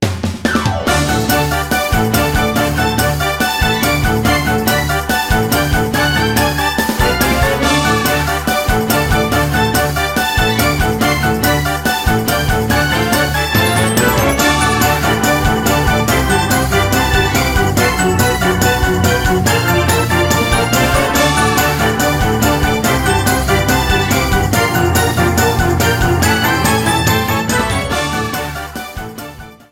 Ripped from the ISO
Faded in the end
Fair use music sample